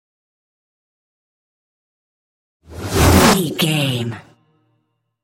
Trailer dramatic raiser short flashback
Sound Effects
Fast paced
In-crescendo
Atonal
intense
tension
riser